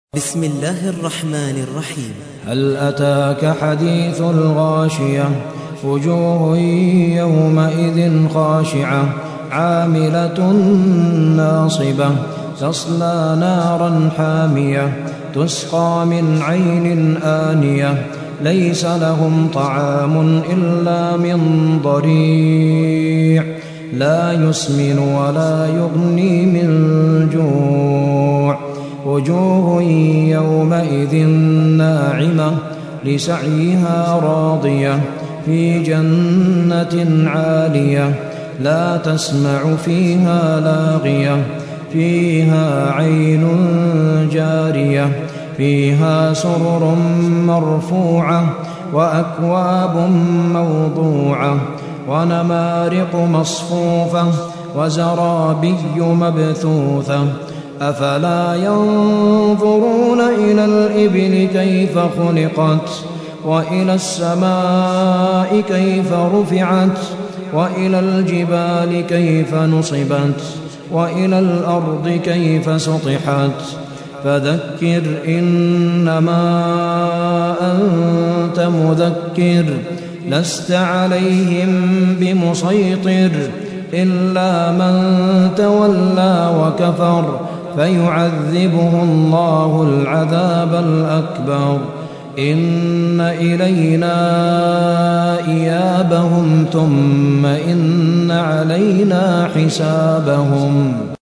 88. سورة الغاشية / القارئ